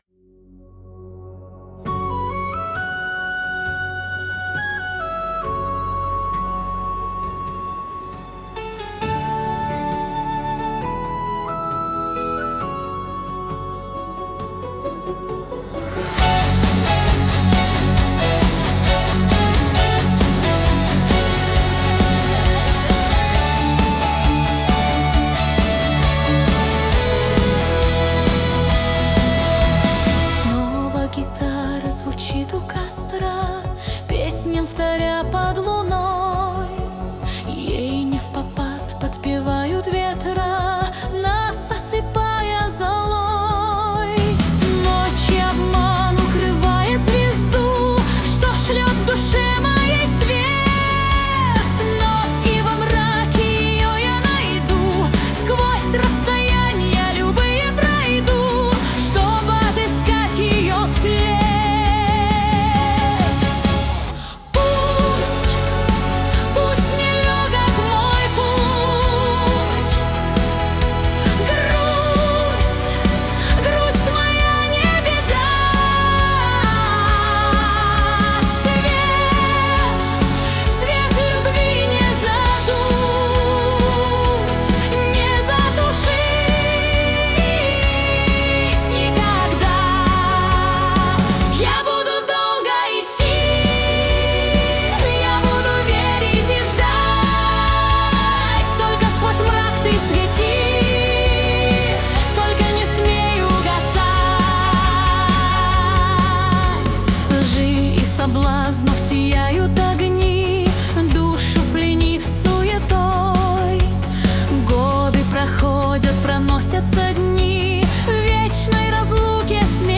Костровые